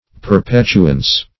Perpetuance \Per*pet"u*ance\, n.